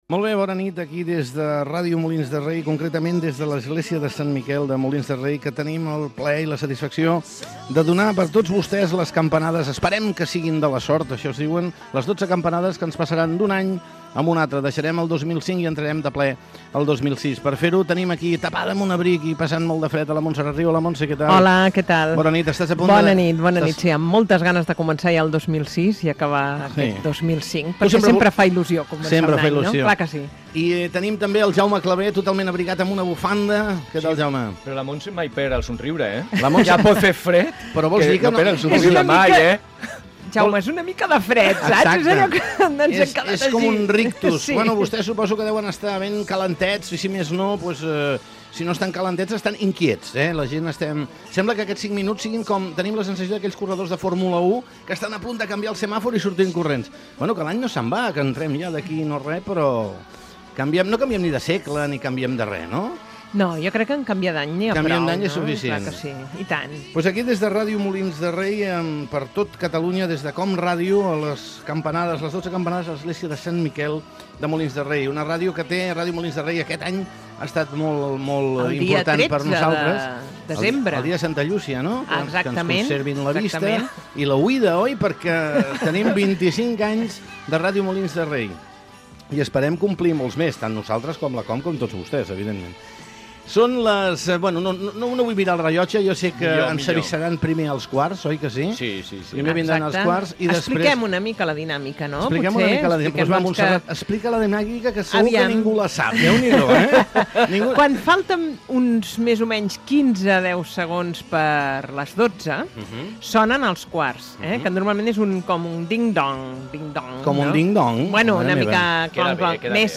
Prèvia a les campanades de cap d'any des de l'Església de Sant Miquel de Molins de Rei, en el 25è aniversari de l'emissora municipal.
Entreteniment